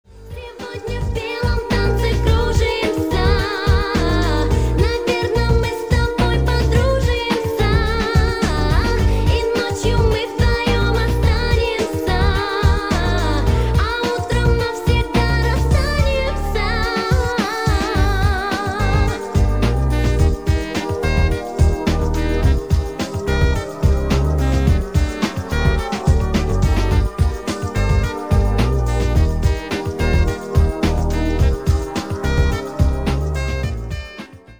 ретро